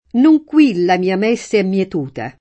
per le t$nere v%rdi m$SSi al pL#no] (Carducci); adattare il terreno a mèssi migliori [adatt#re il terr%no a mm$SSi mil’l’1ri] (F. Martini); un mare Dorato di tremule mèssi [um m#re dor#to di tr$mule m$SSi] (Pascoli); Non qui la mia mèsse è mietuta [